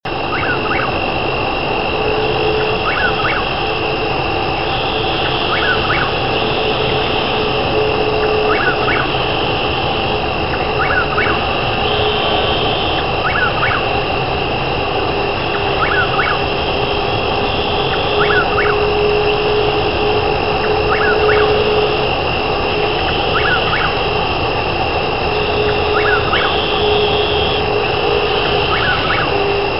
Here you will encounter a few distant Gastrophryne carolinensis, but the focal point of this particular tone is the strange nightbird called the Chuck-Will's-Widow, not too far from a distant freeway..... and a few crickets!